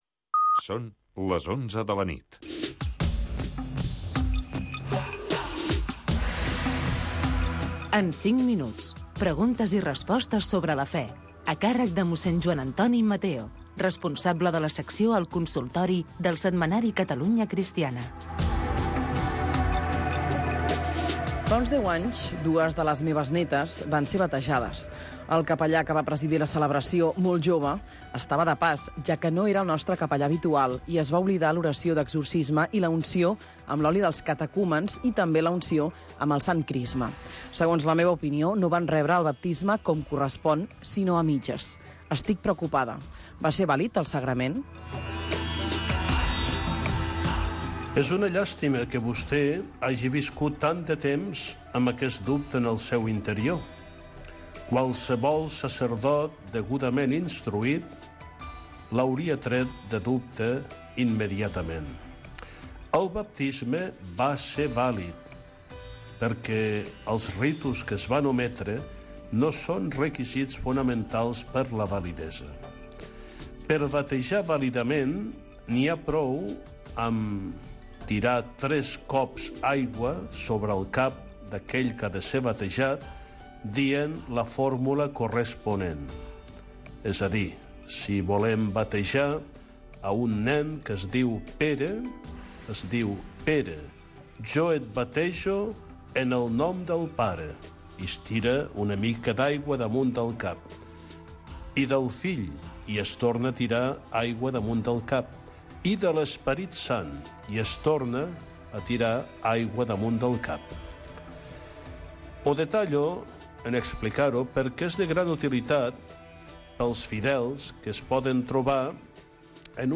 Espai on els oients poden fer consultes de tipus religiós